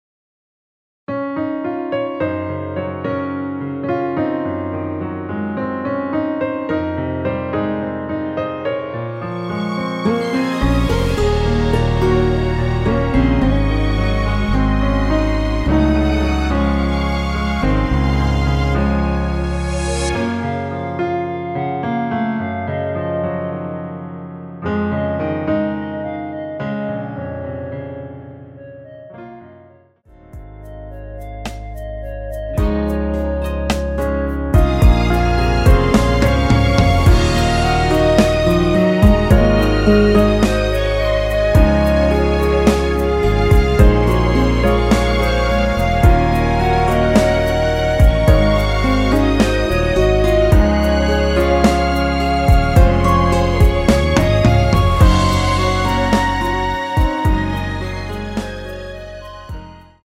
원키에서(+8)올린 멜로디 포함된 MR입니다.
Db
멜로디 MR이라고 합니다.
앞부분30초, 뒷부분30초씩 편집해서 올려 드리고 있습니다.
중간에 음이 끈어지고 다시 나오는 이유는